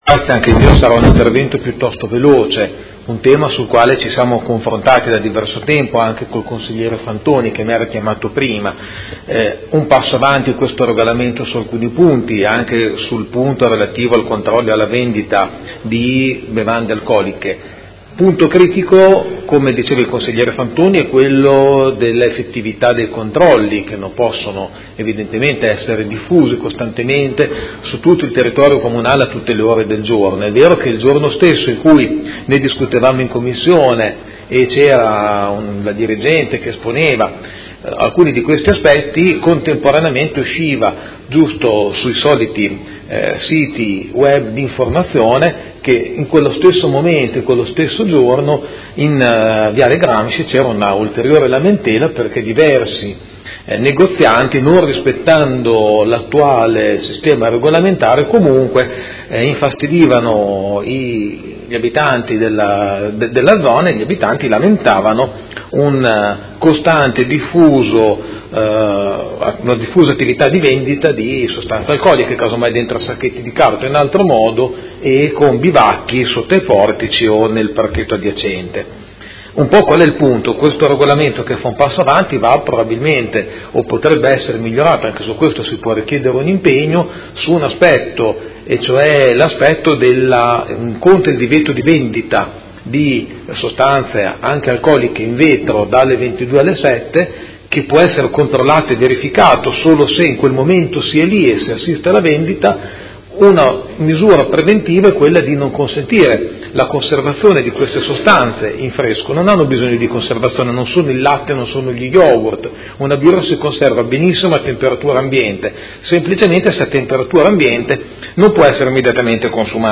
Seduta dell'11/01/2018 Dibattito. Nuovo Regolamento di Polizia Urbana, approvazione e Ordine del giorno nr. 4699 Modifica articolo 10 del regolamento Polizia Urbana - Articoli pirotecnici e fuochi d'artificio.